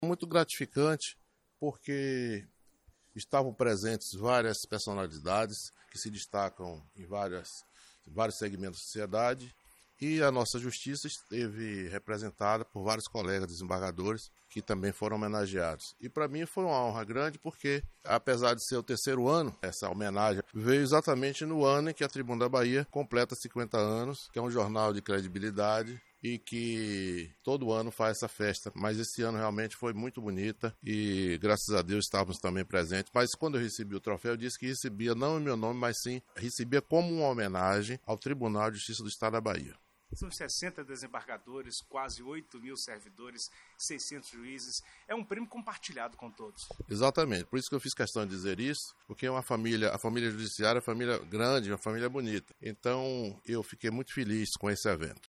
A solenidade ocorreu no Casarão Fróes da Mota, na cidade de Feira de Santana, no dia 12/12, e marcou o encerramento das comemorações pelos 50 anos do Jornal Tribuna da Bahia.